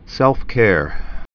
(sĕlfkâr)